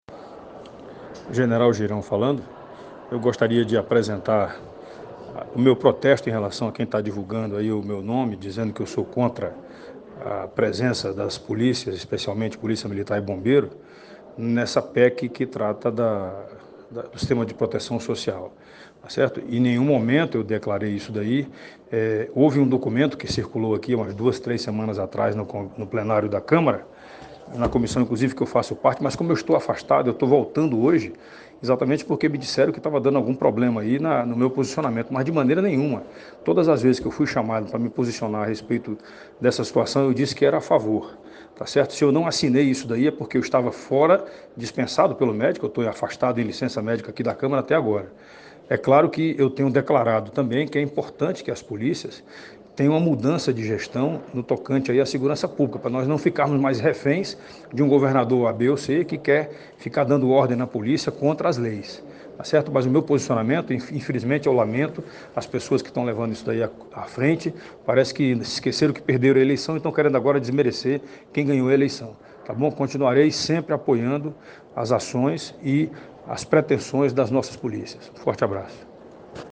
O deputado federal general Girão divulgou áudio, nesta terça-feira, 20, à noite, protestando contra a nota de repúdio publicada pela Associação dos Oficiais Militares do Rio Grande do Norte, que o criticou por não “assinar a emenda ao projeto de lei 1.645/19, que garante aos policiais militares e bombeiros militares o mesmo tratamento social dos militares da União”.
Ouça o que diz o deputado: